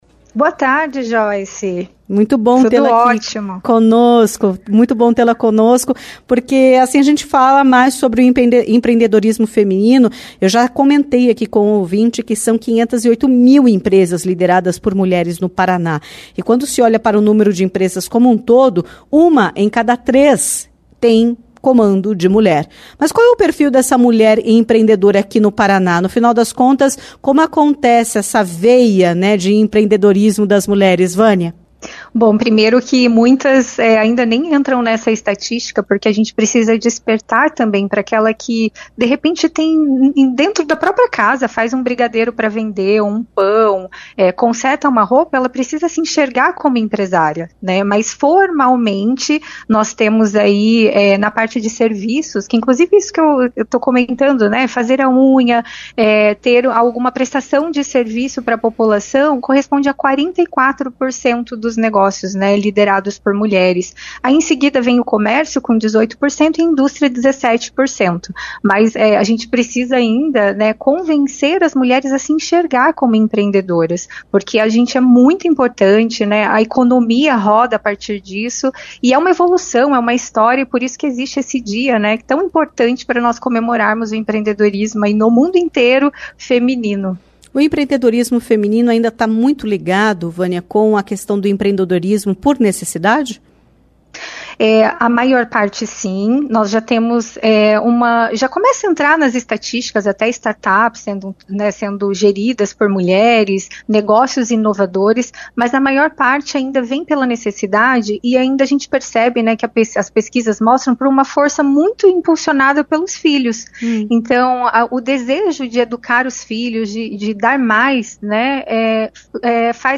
Entrevista-19-11.mp3